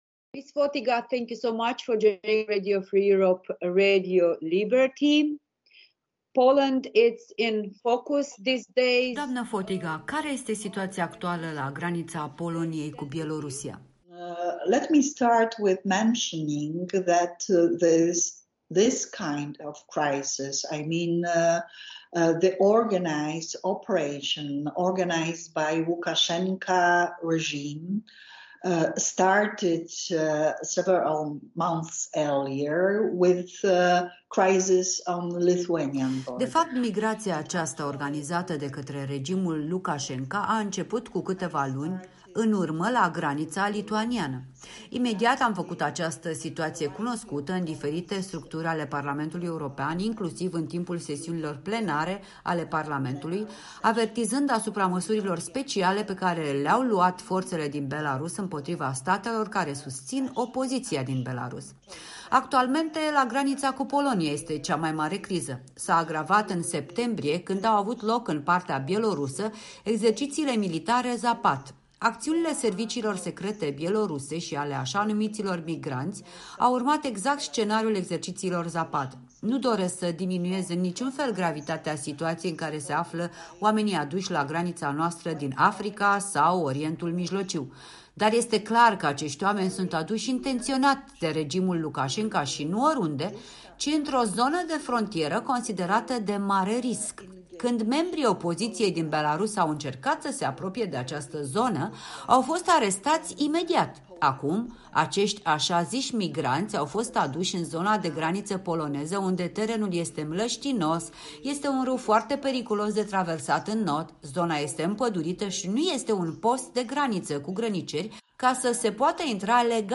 Interviu cu eurodeputata Anna Fotyga